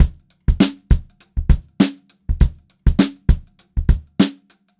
100STBEAT2-L.wav